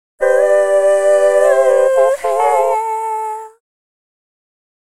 Original （ボイスねたを DecaBuddy でハモらしてます）